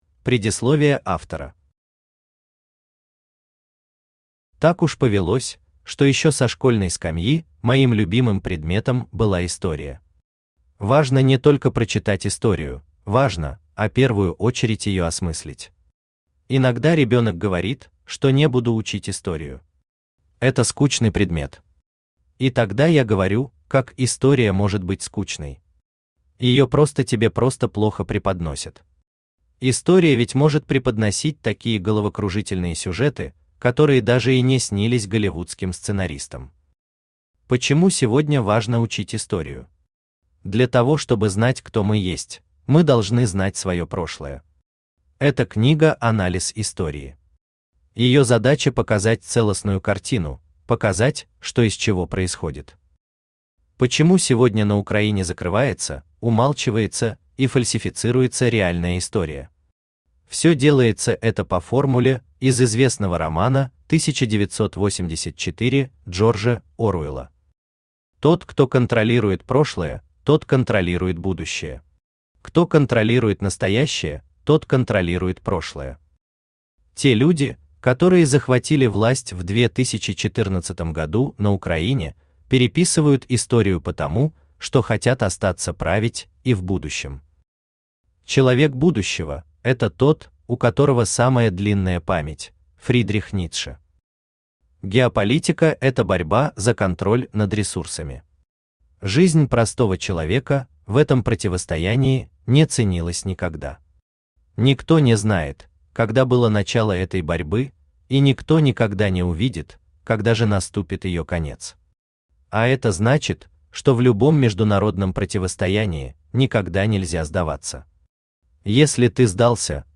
Аудиокнига Евромайдан – захват Украины | Библиотека аудиокниг
Читает аудиокнигу Авточтец ЛитРес.